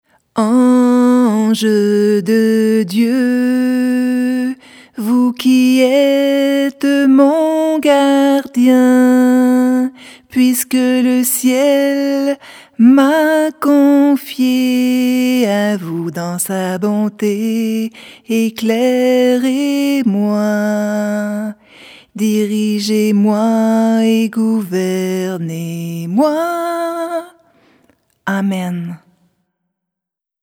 ange_gardien_chanter.mp3